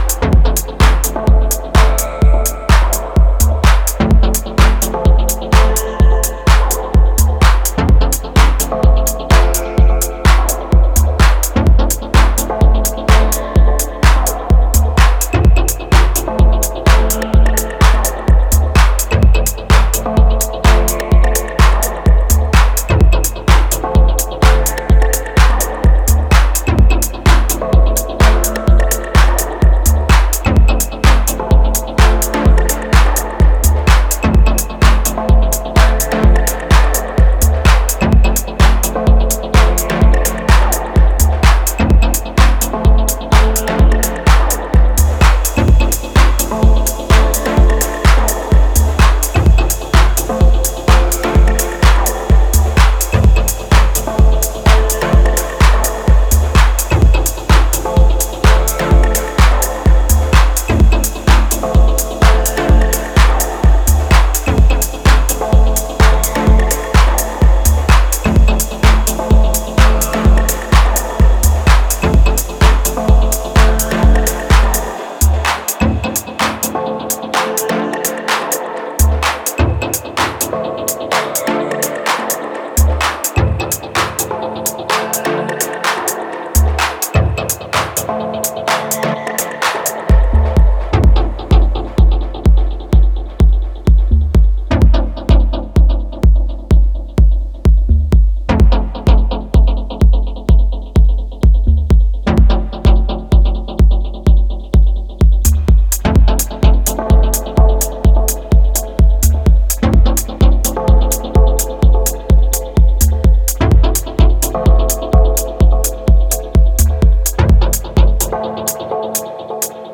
アクアティックに遊泳するダブテック・ハウス